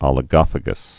(ŏlĭ-gŏfə-gəs, ōlĭ-)